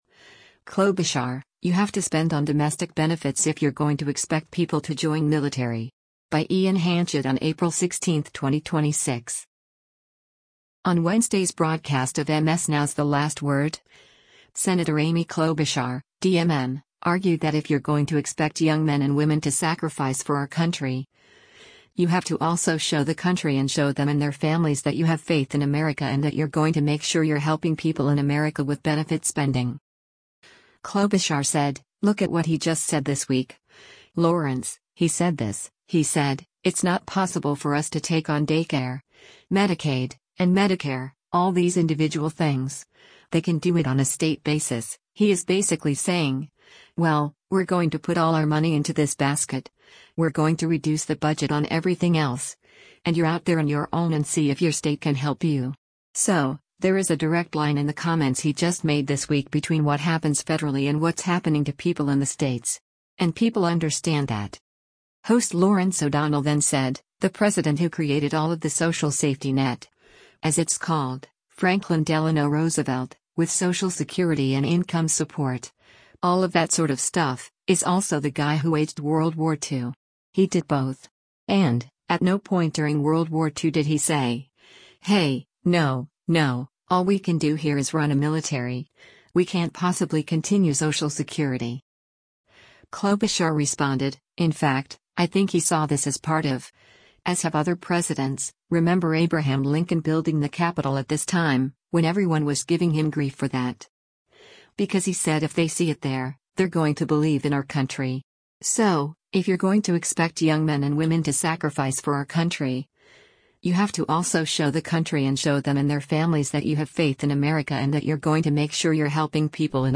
On Wednesday’s broadcast of MS NOW’s “The Last Word,” Sen. Amy Klobuchar (D-MN) argued that “if you’re going to expect young men and women to sacrifice for our country, you have to also show the country and show them and their families that you have faith in America and that you’re going to make sure you’re helping people in America” with benefit spending.